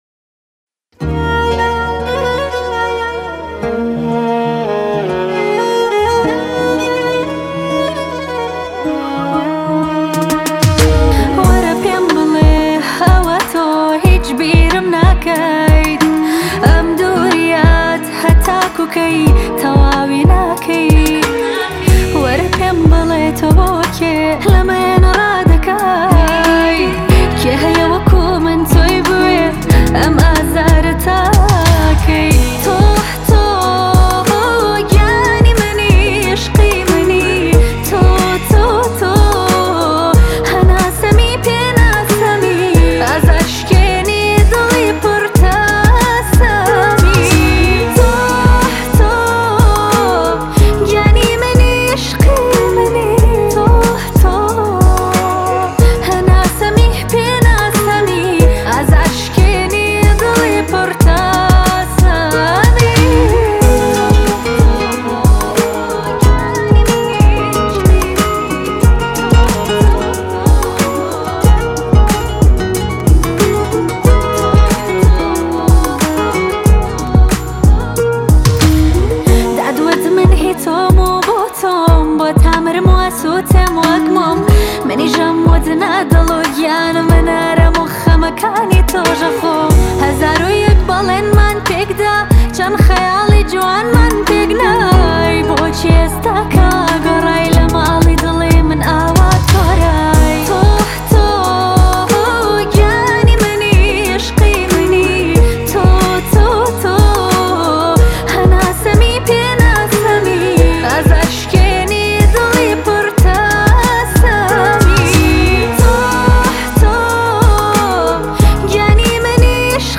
آهنگ جدید کردی